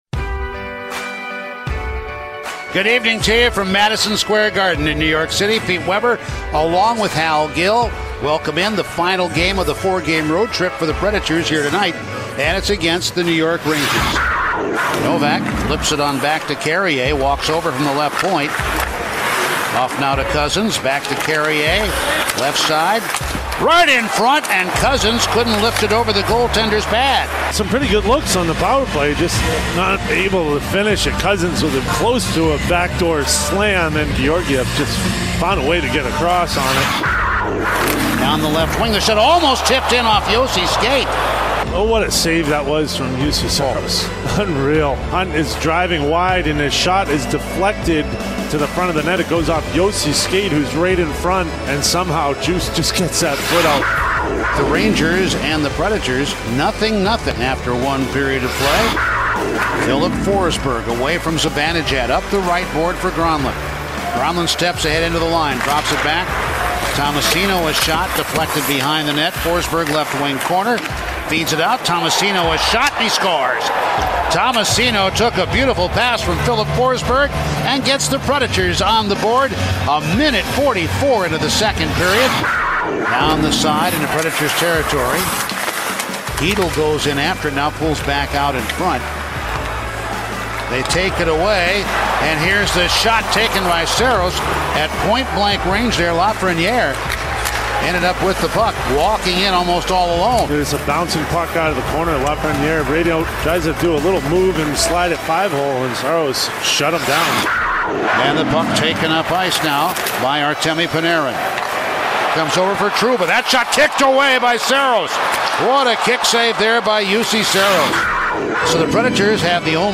Full radio highlights from the Preds 1-0 win at MSG